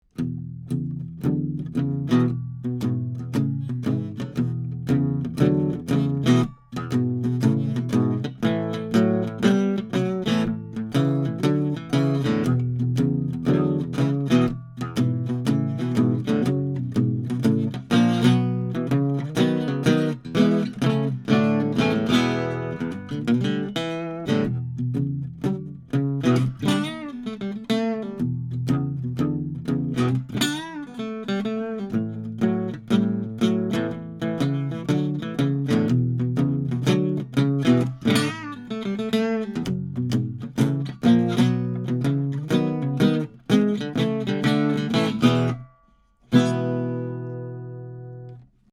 1937 Gibson L7 Acoustic Archtop Guitar
This 17" archtop has the rare picture-frame inlays, and parallel bracing, and has a warmth and clarity that you can only get in a Pre-War archtop.
CLICK HERE for additional 1270 DPI Hi-Res photos of this L7, and here are some MP3s of me playing this guitar, to give you an idea of what to expect. Signal chain is a pair of Neumann U87s through a Flickinger Audio TwinFlicks preamp into a Metric Halo ULN-8 interface:
L7U87sTFlicksBlues.mp3